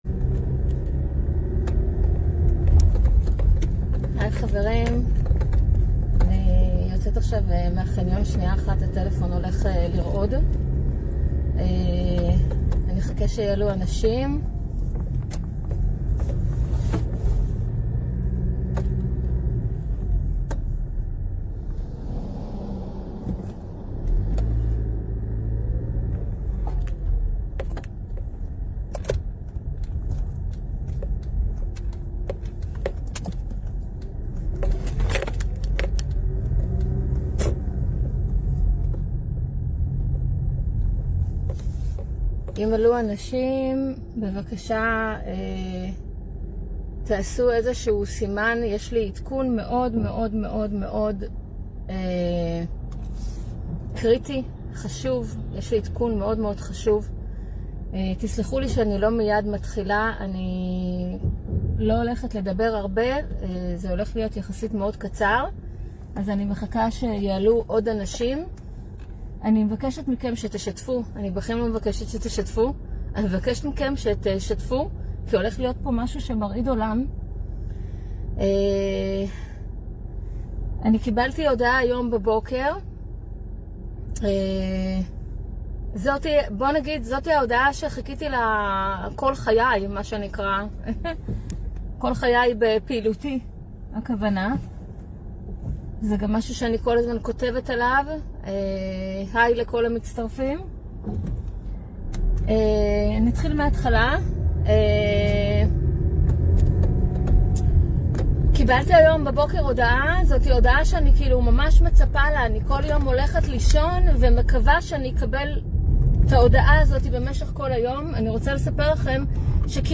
שידור חי